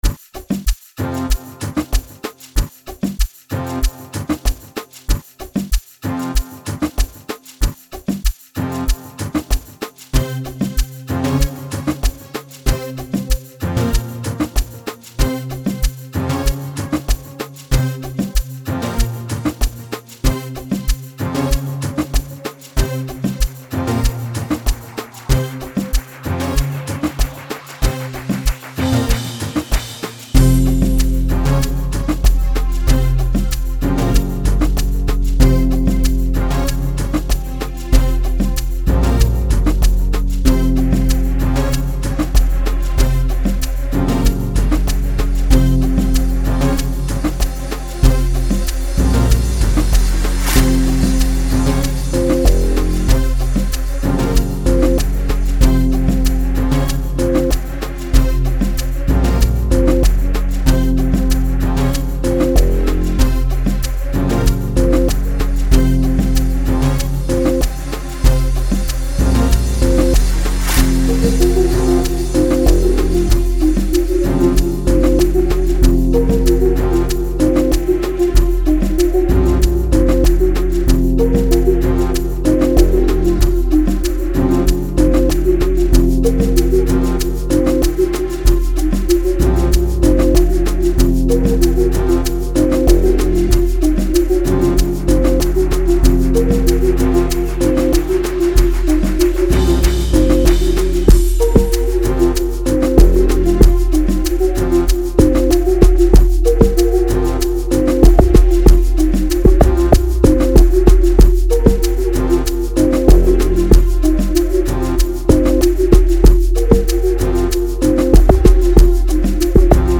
07:01 Genre : Amapiano Size